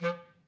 DCClar_stac_F2_v2_rr2_sum.wav